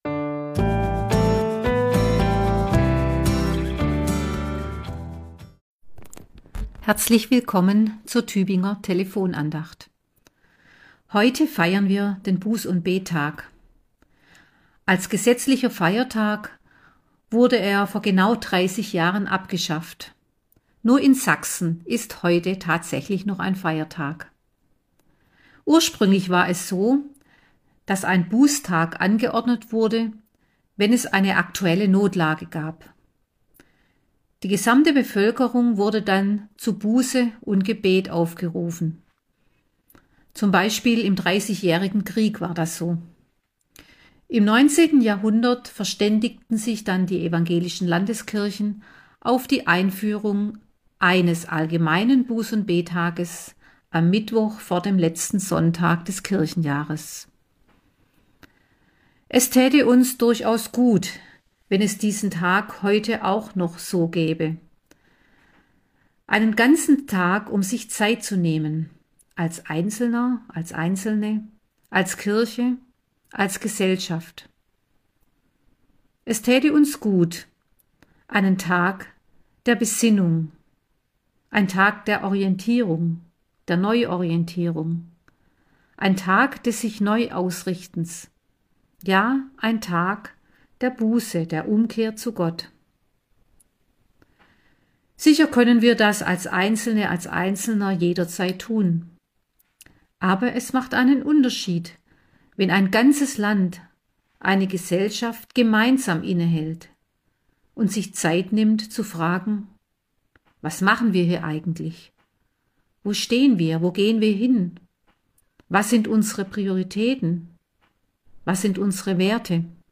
Andacht zur Tageslosung